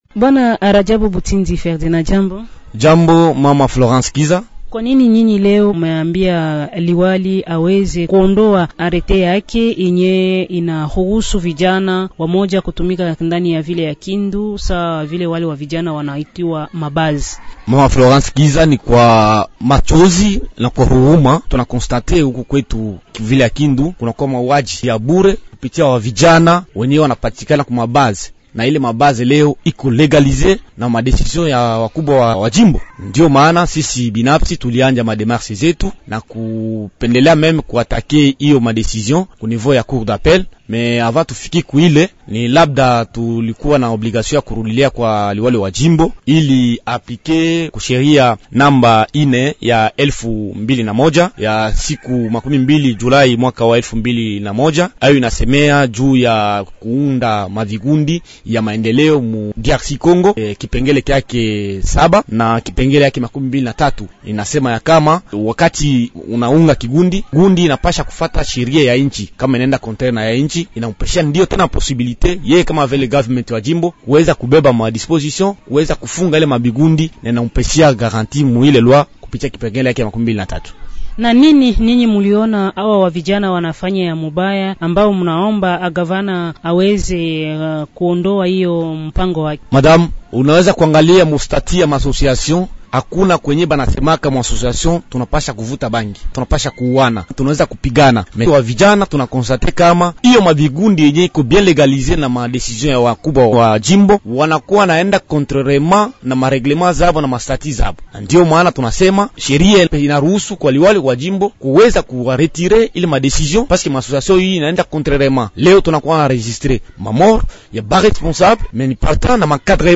Anatujia kutoka Kindu katika jimbo la Maniema.